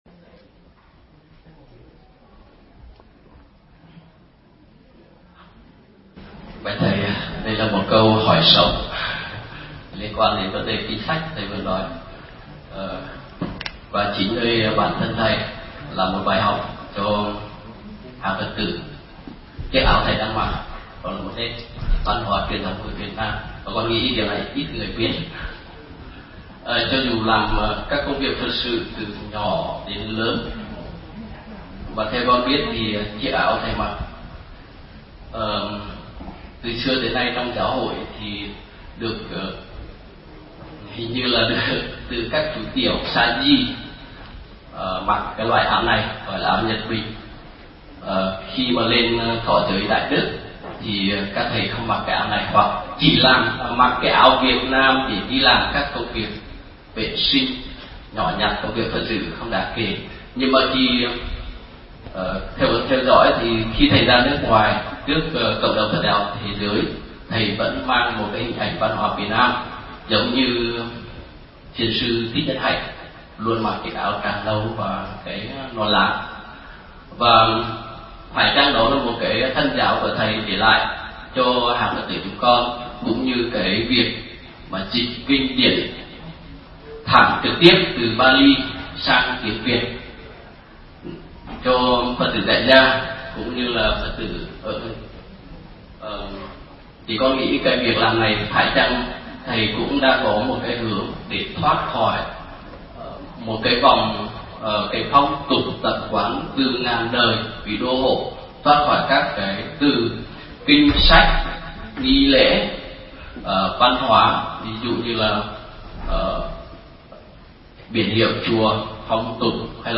Vấn đáp: Áo nhật bình của tu sĩ Việt Nam – Thuyết Giảng Thích Nhật Từ